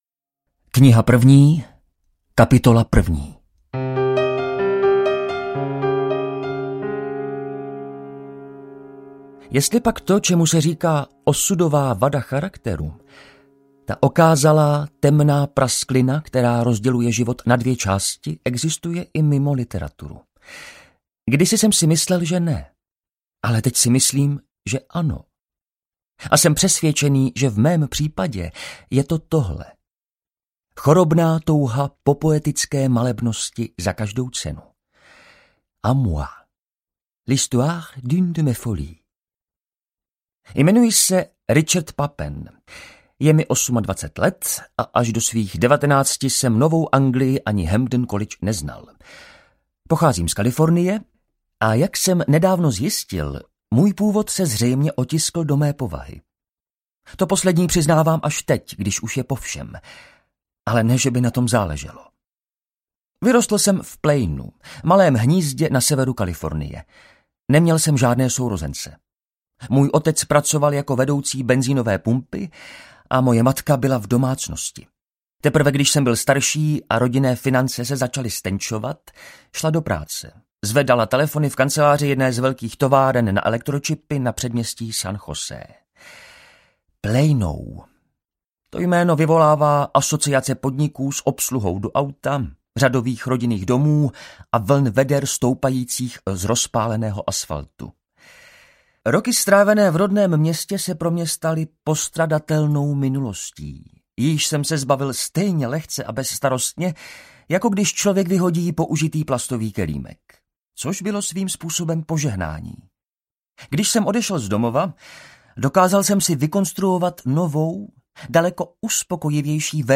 Tajná historie audiokniha
Ukázka z knihy